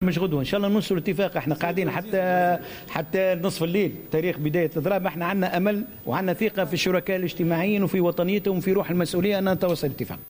أكد وزير الشؤون الاجتماعية، محمد الطرابلسي في تصريح للقناة الوطنية الأولى أن باب التفاوض مازال مفتوحا من أجل التوصل إلى اتفاق ينهي الإضراب المزمع تنفيذه بمحطات بيع الوقود.